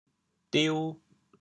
反切 端优 调: 抵 国际音标 [tiu]